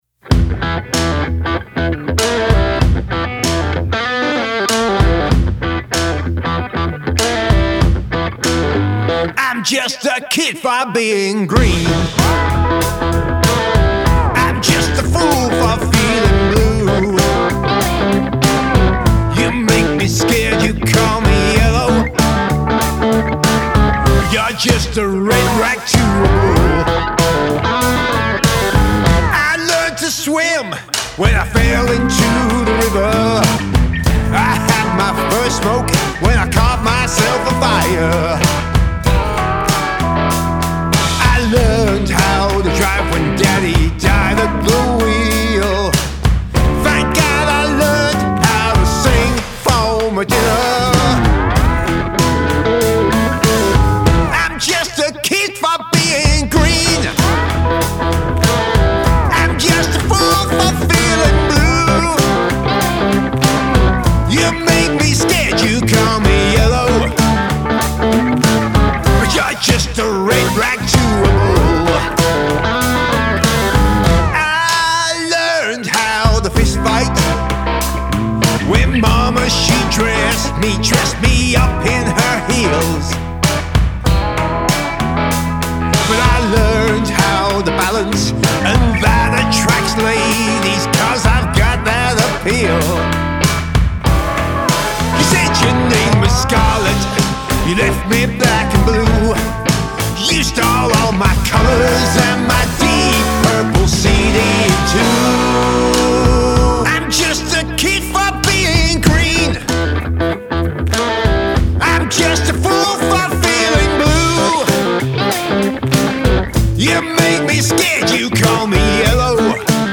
Male Vocal, Guitar, Pedal Steel Guitar, Bass Guitar, Drums